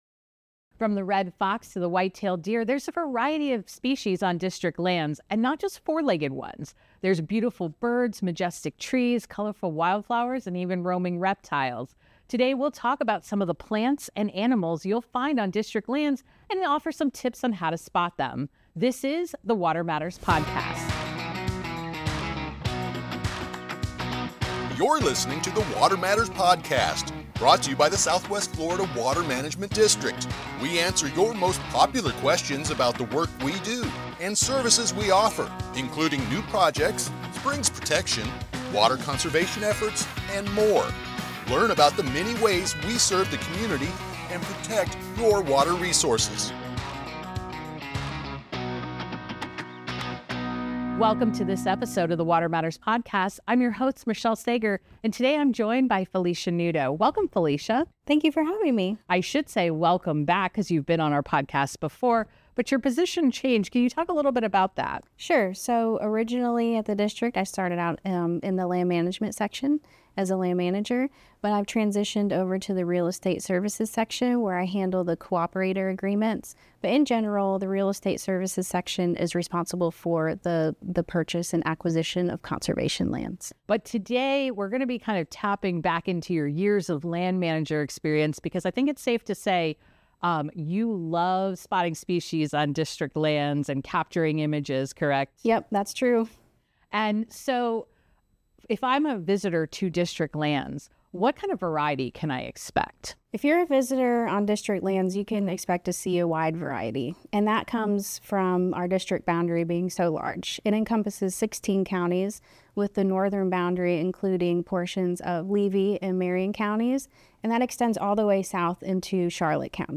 We talked to an expert from Hernando County on the latest episode of the Water Matters podcast.